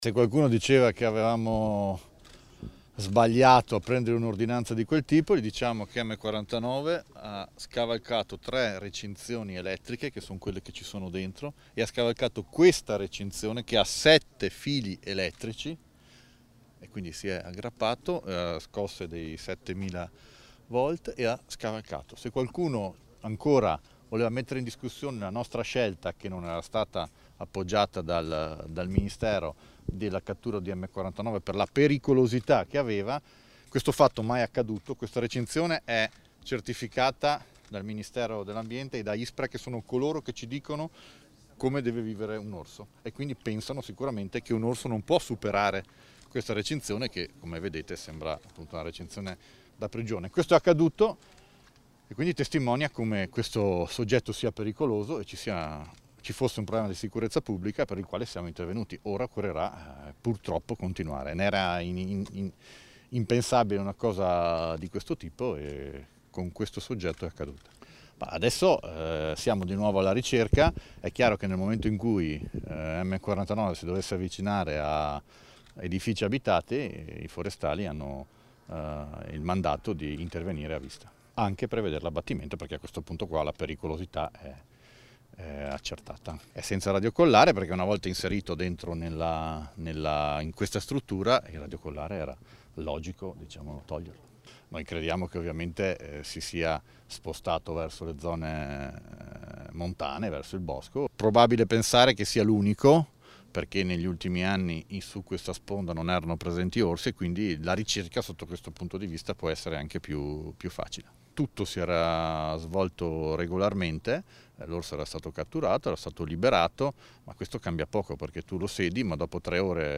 Scarica il file intervista pres. Fugatti casteller 15_07_2019 (1).mp3